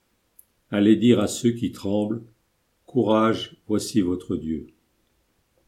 Antienne
Antienne-Zacharie-Allez-dire-a-ceux-qui-tremblent.mp3